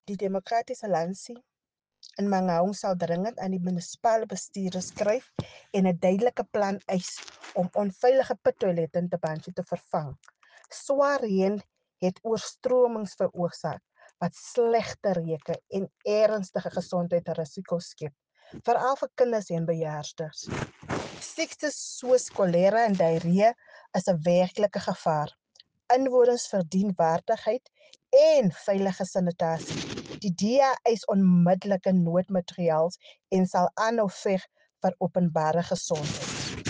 Afrikaans soundbite by Cllr Raynie Klaasen.